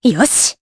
Aselica-Vox_Happy4_jp.wav